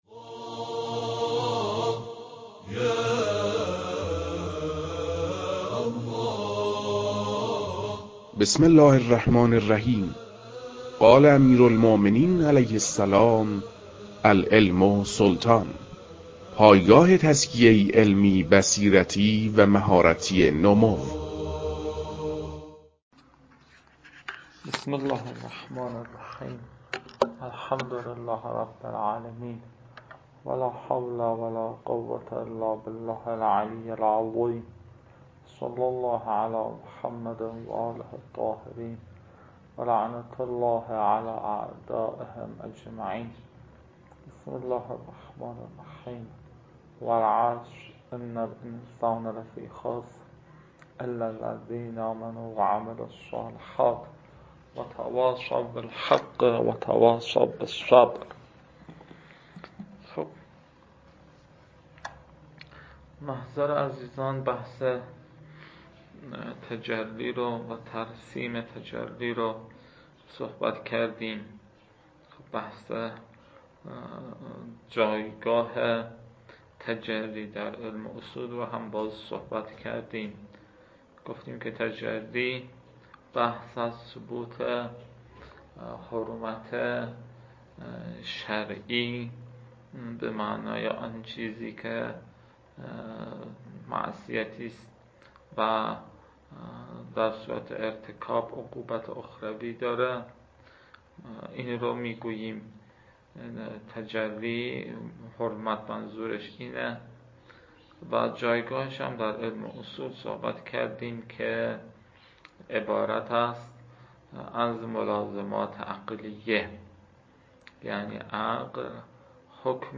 در این بخش، فایل های مربوط به تدریس مبحث رسالة في القطع از كتاب فرائد الاصول متعلق به شیخ اعظم انصاری رحمه الله